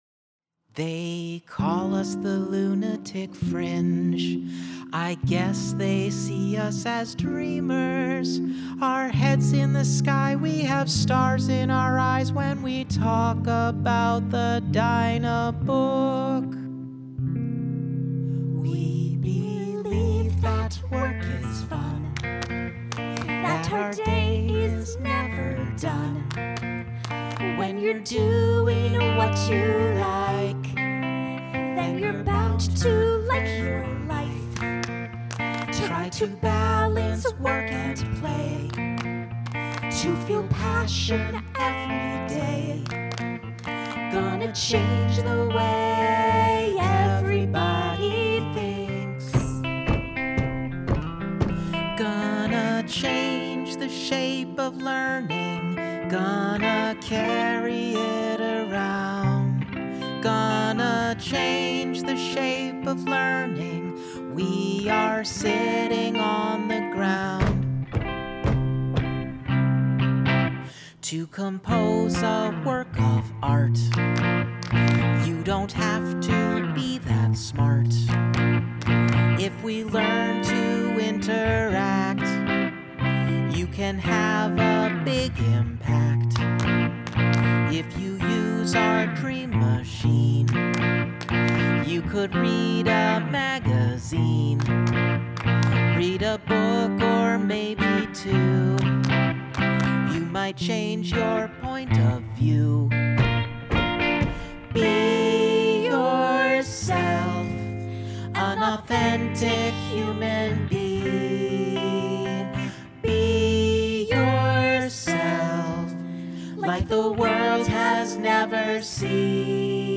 A musical about creation of first interactive PC at Xerox PARC.
Note: The following includes charts, context, and rough demos (some rougher than others).
(Scene 3a) Shape of Learning (solo with group):
shape-of-learning-demo-new-intro.mp3